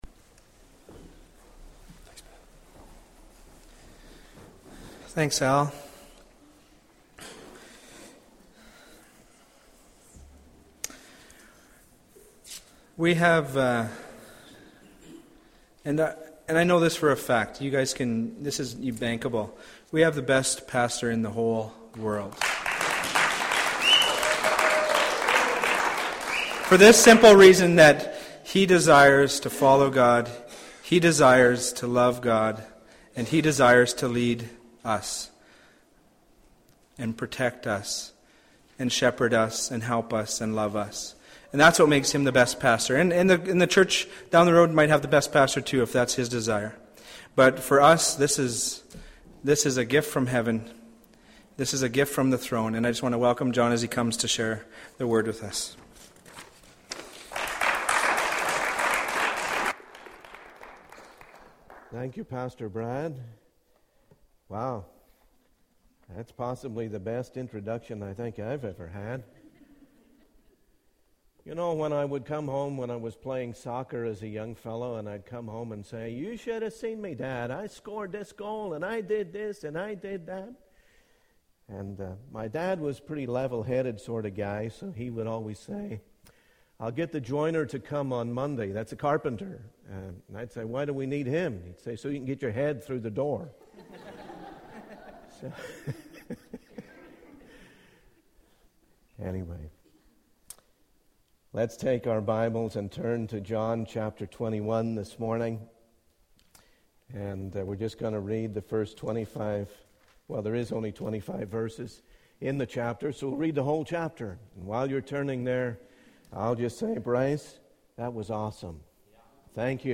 In this sermon, the speaker emphasizes that Jesus wants to be involved in every aspect of our lives, not just the big things. He teaches that Jesus provides for His servants, including food, fire, fellowship, and growth.